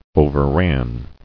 [o·ver·ran]